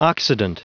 Prononciation du mot occident en anglais (fichier audio)
Prononciation du mot : occident